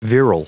Prononciation du mot virile en anglais (fichier audio)
Prononciation du mot : virile